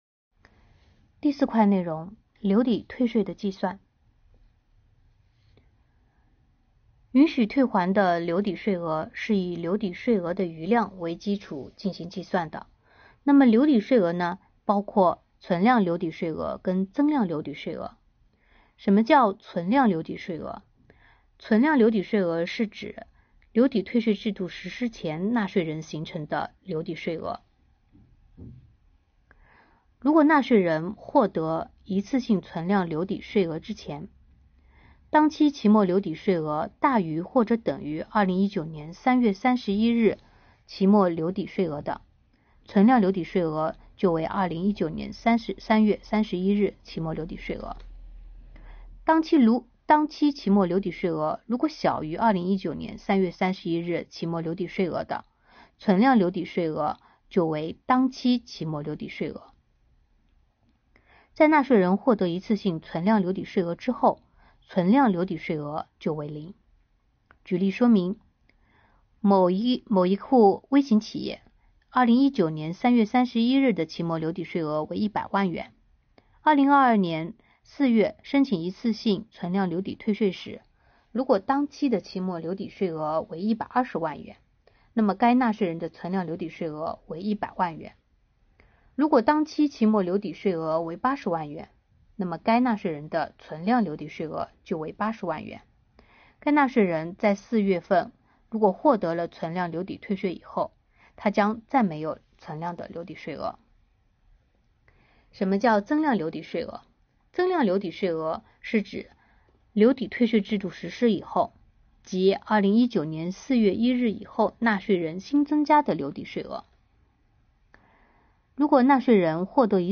【奉贤税务】“嗨！小贤”微课堂之2022年增值税留抵退税的计算